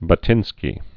(bŭt-ĭnskē)